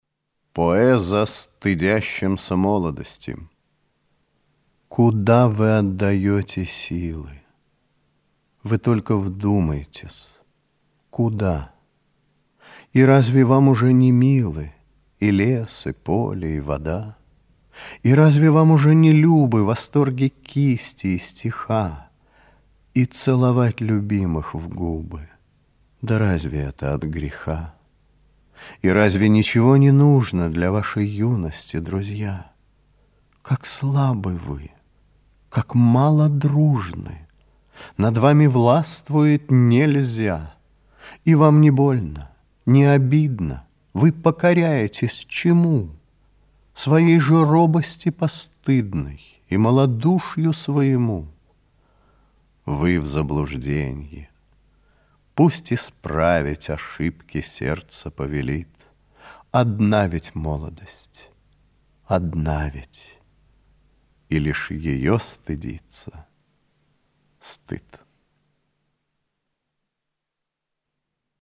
2. «Игорь Северянин – Поэза стыдящимся молодости (читает Виктор Татарский)» /